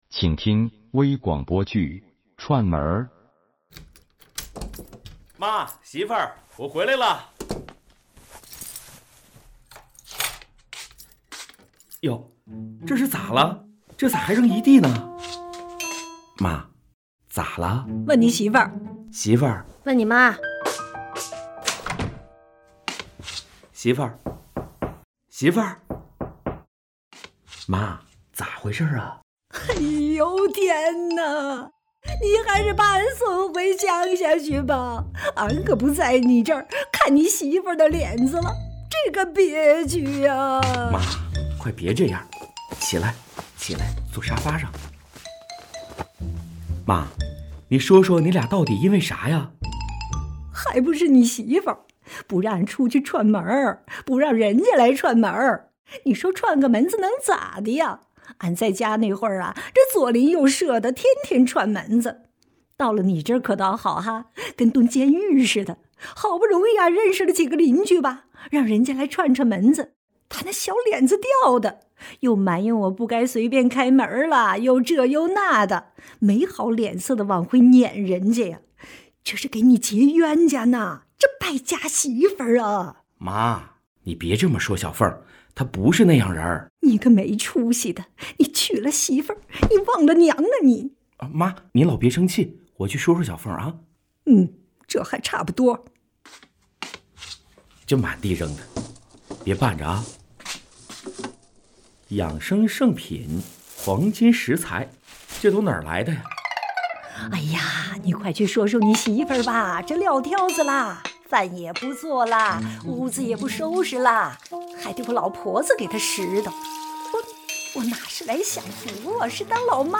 • 广播类型：微广播剧